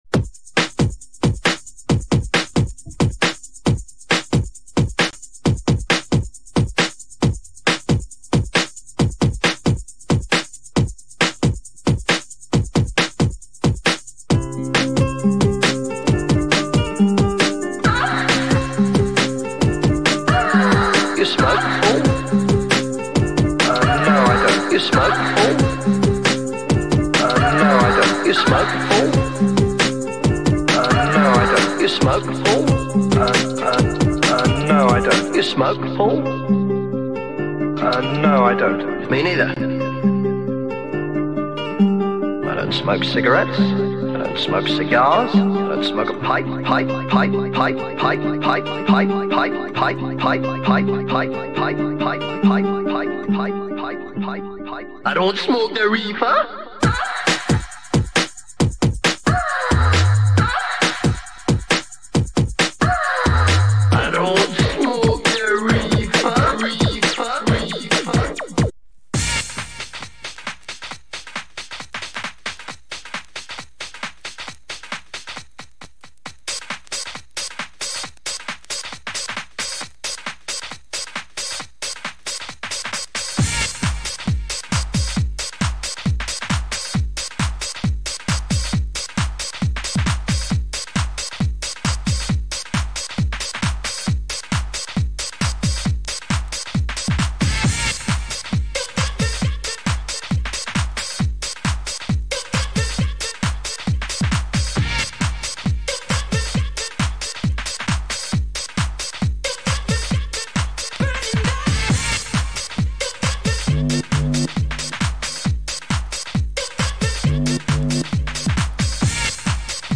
Hour of random garage
Vocals Breaks Electro